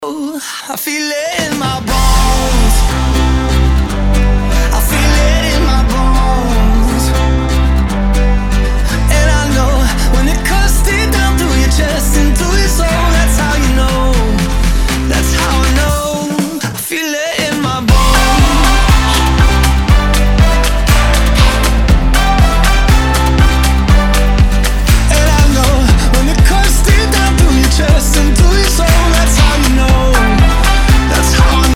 Duo asal Swedia
Meski hasil akhirnya memang lebih nge-pop ketimbang dance.